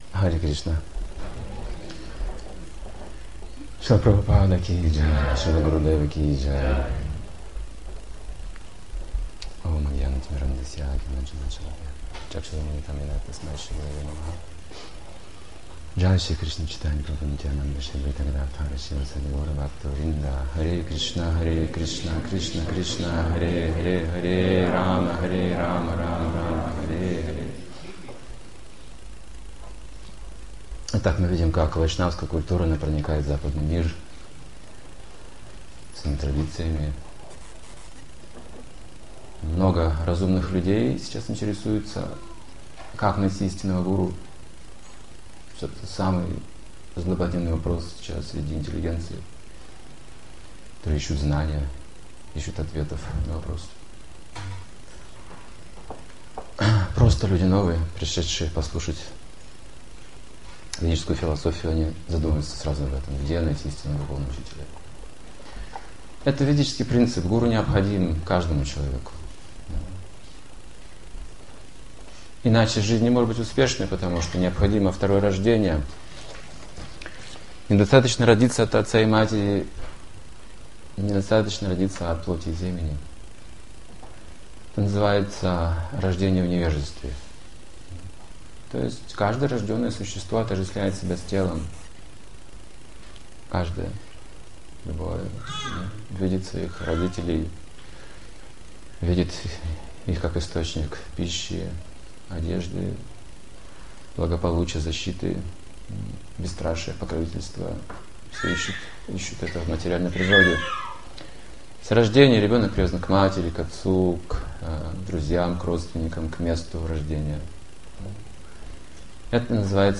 Лекция о вере, духовной культуре, обетах и воспевании Святого Имени.